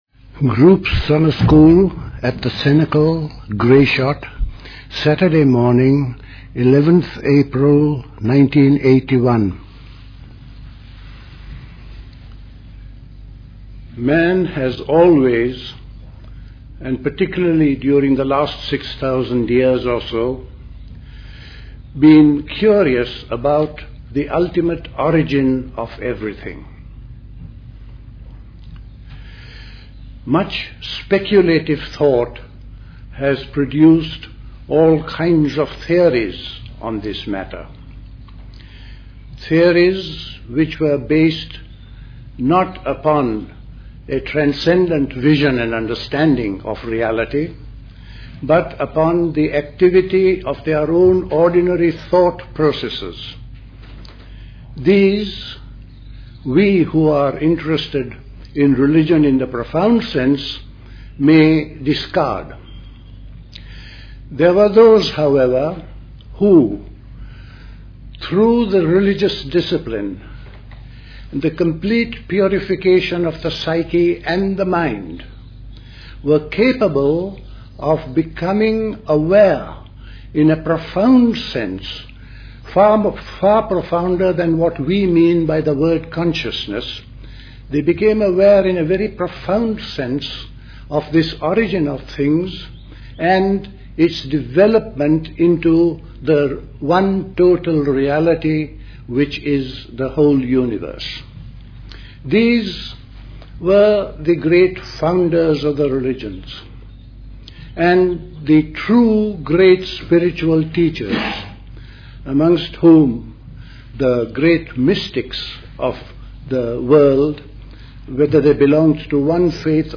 at the Convent of the Cenacle, Grayshott, Hampshire on 11th April 1981